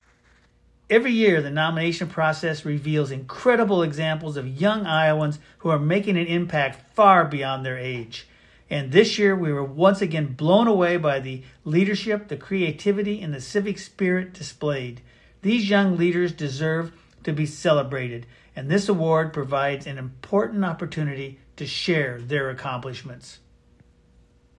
A soundbite from Secretary Pate is available for your use here.